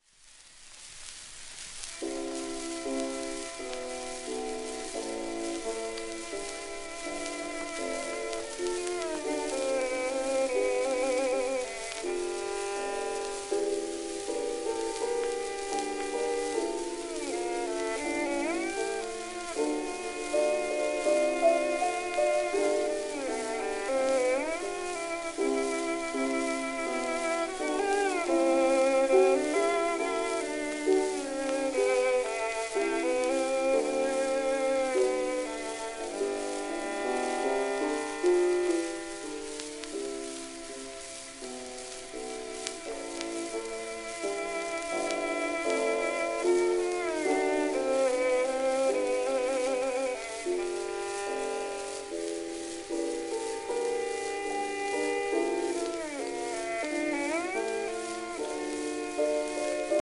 10インチ片面盤
1912年録音
旧 旧吹込みの略、電気録音以前の機械式録音盤（ラッパ吹込み）